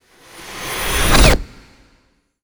magic_conjure_charge2_03.wav